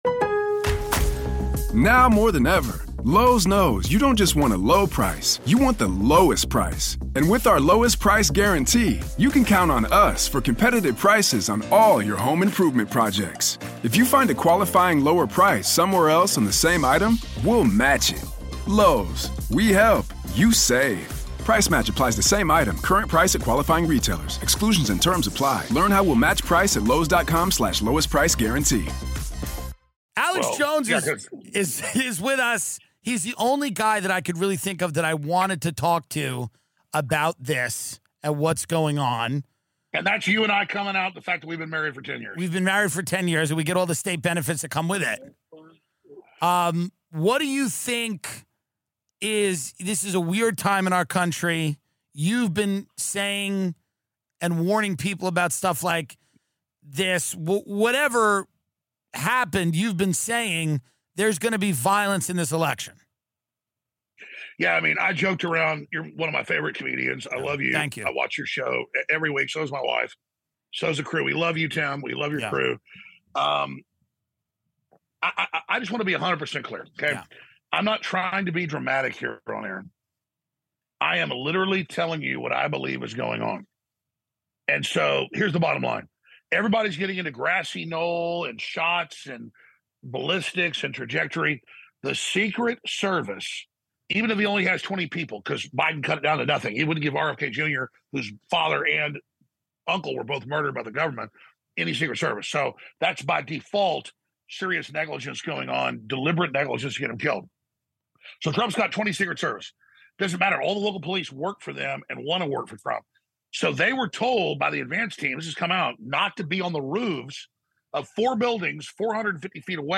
Tim sits down with a prominent Austin broadcaster on the attempted assassination of former President Donald Trump, the Secret Service’s lapse in security, how the nation is reacting and what this means for its future.